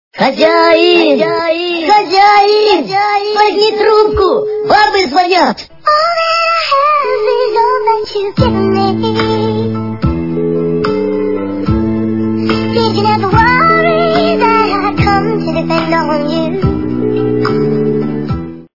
» Звуки » Смешные » Хозяин, возьми трубку! - Бабы звонят....